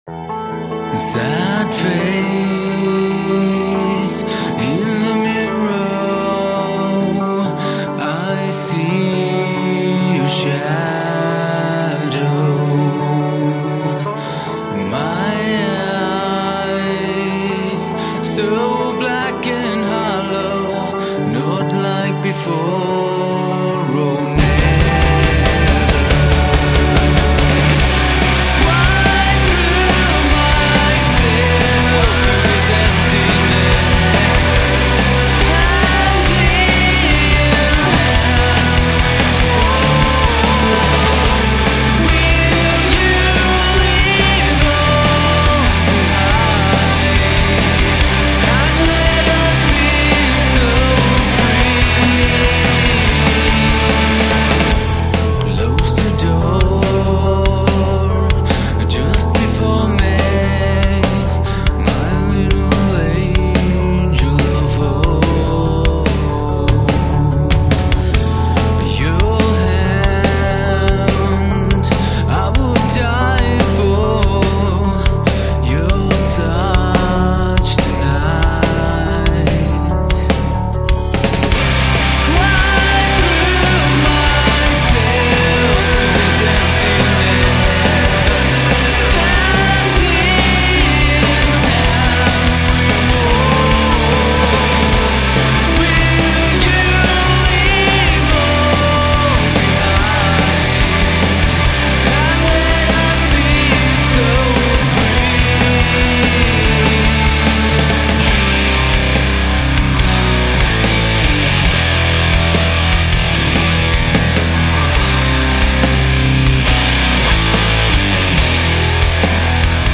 Genre: rock gothique